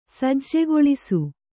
♪ sajjegoḷisu